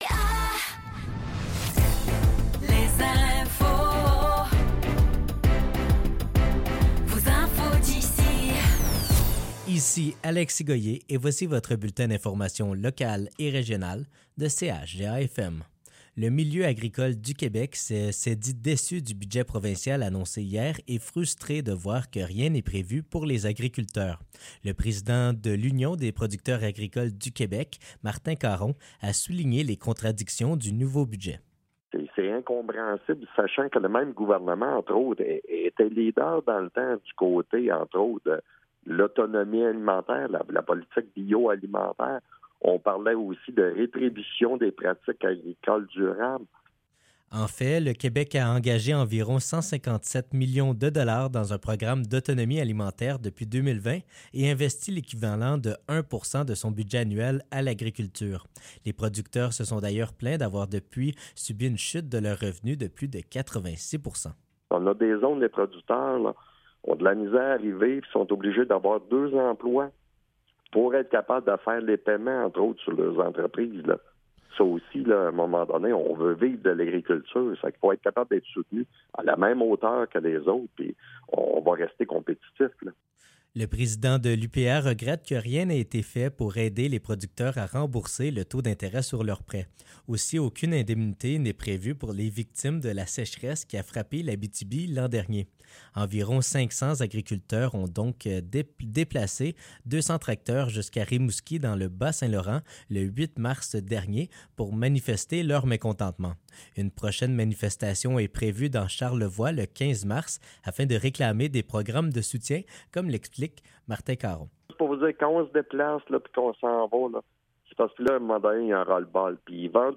Nouvelles locales - 13 mars 2024 - 15 h